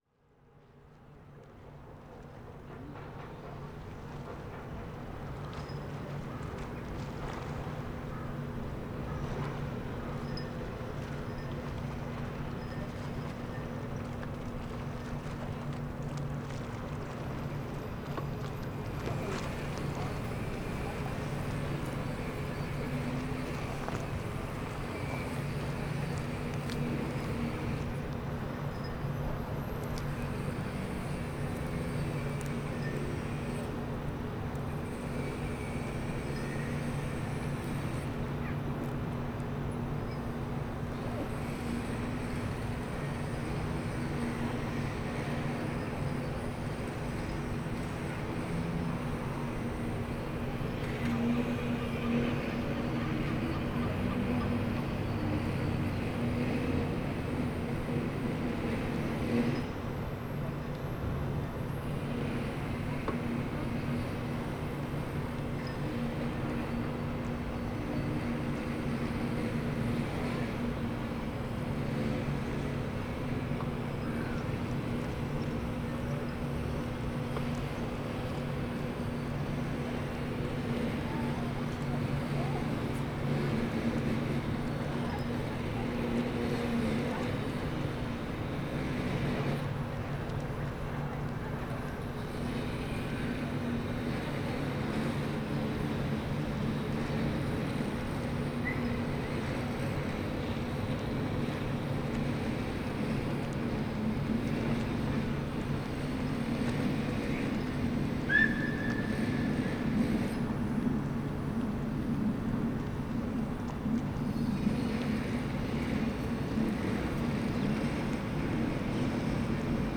recorded with Tascam DR40X recorder and Sanken CS-3 super-cardioid mono microphone